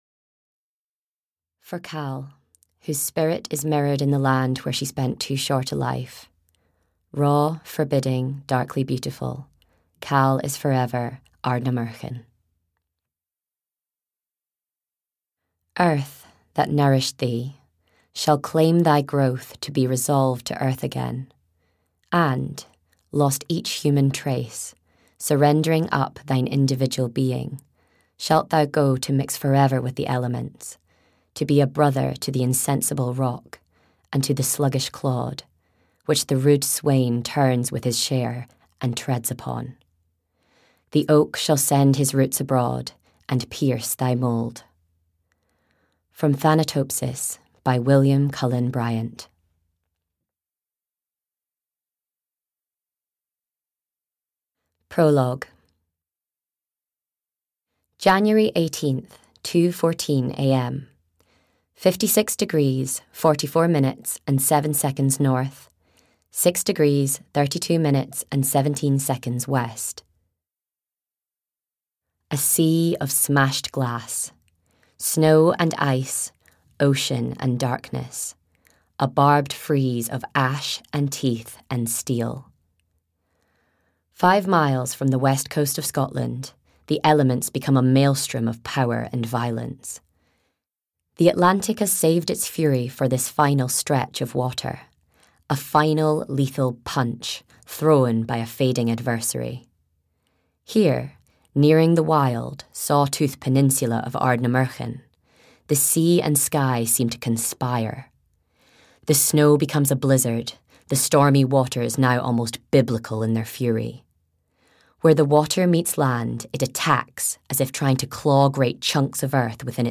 The Guest House (EN) audiokniha
Ukázka z knihy